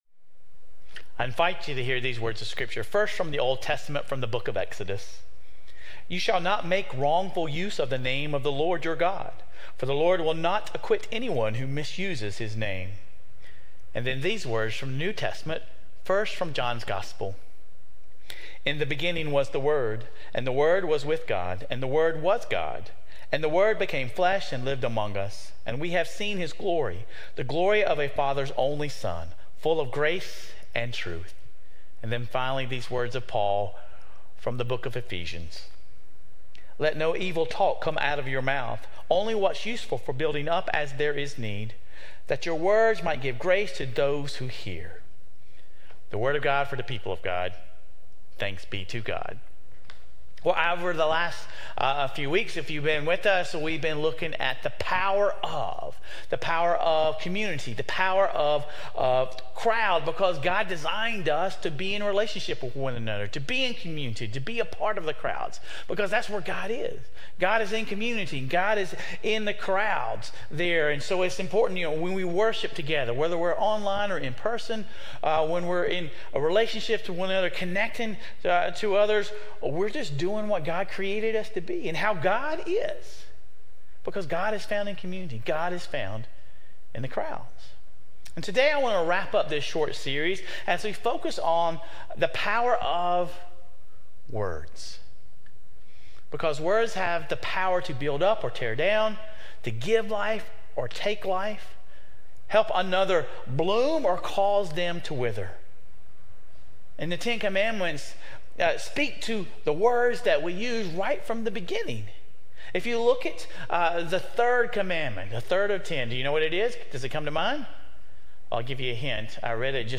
Sermon Reflections: How can our words build up others or tear them down?